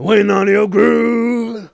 GROOVE1A.WAV